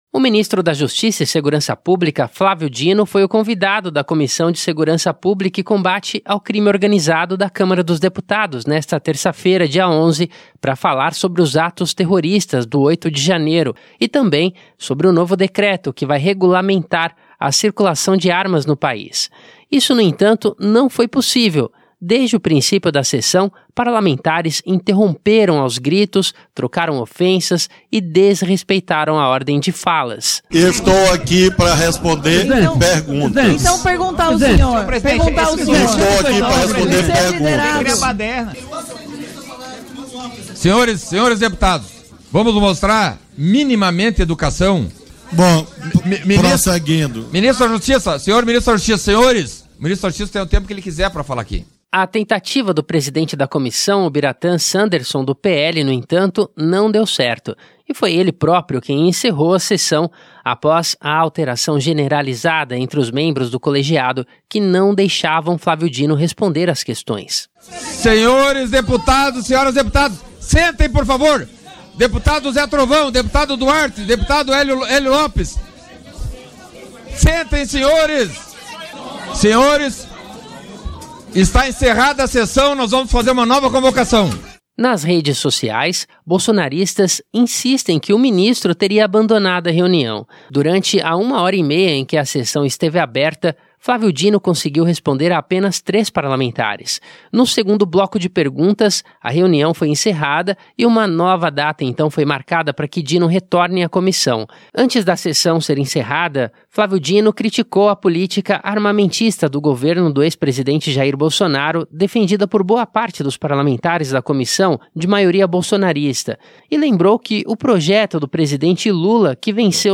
Tumulto
Flávio Dino, ministro da Justiça e Segurança Pública, era o convidado da Comissão de Segurança Pública e Combate ao Crime Organizado da Câmara dos Deputados desta terça-feira (11), para falar sobre os atos terroristas de 8 de janeiro e o novo decreto que regulamentará a circulação de armas no país.
Desde o princípio da sessão, parlamentares se interrompiam aos gritos, trocavam ofensas e desrespeitavam a ordem de falas. O deputado federal Ubiratan Sanderson (PL-RS) foi quem encerrou a sessão, após alteração generalizada entre os membros do colegiado, que não deixavam Flávio Dino responder às questões.